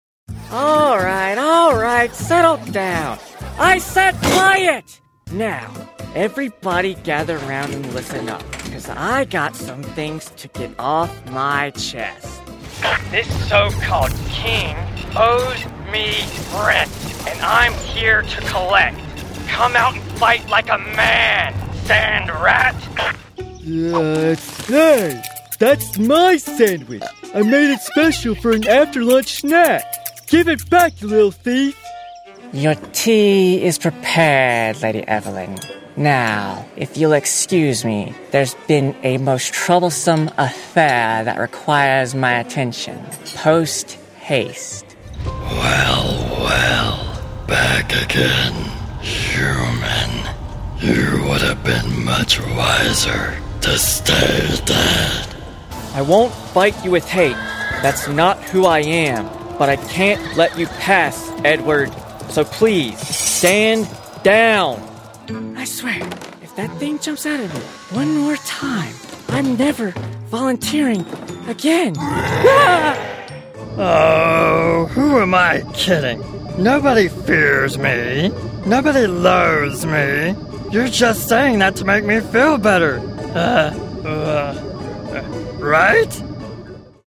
Male Voice Over Talent
Animation
With a voice described as warm, respectful, and genuinely caring, I specialize in creating audio experiences that leave a lasting impression.